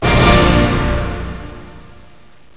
tada.mp3